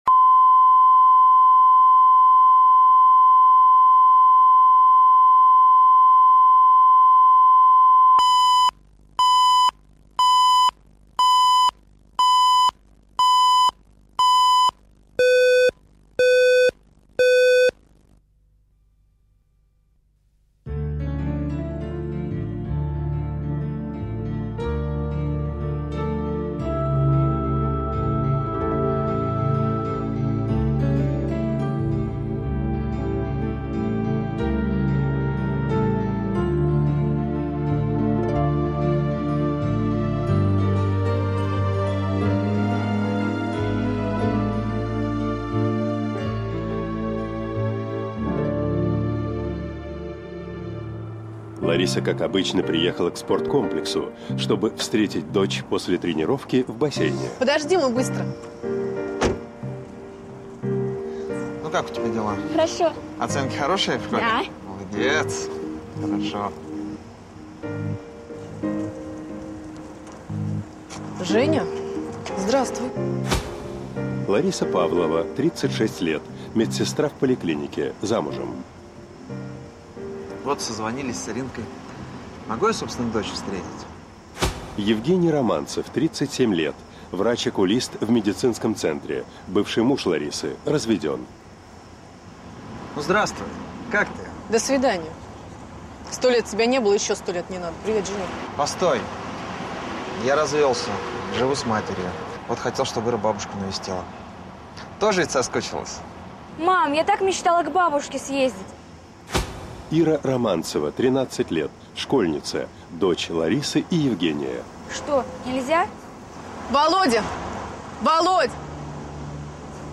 Аудиокнига Посторонний человек | Библиотека аудиокниг